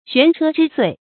懸車之歲 注音： ㄒㄨㄢˊ ㄔㄜ ㄓㄧ ㄙㄨㄟˋ 讀音讀法： 意思解釋： 見「懸車之年」。